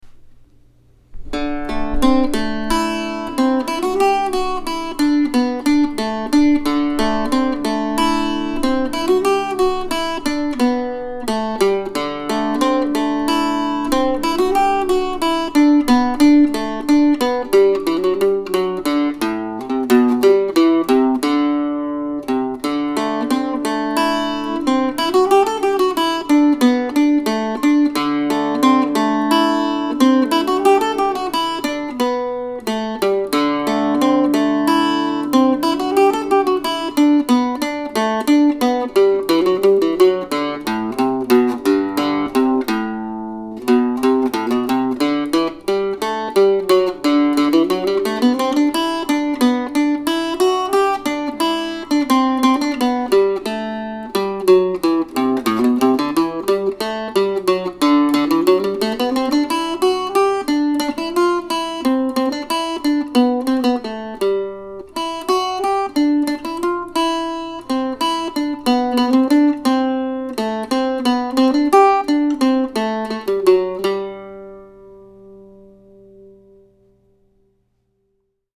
Autumn Suite, November, 2020 (for Octave Mandolin or Mandocello)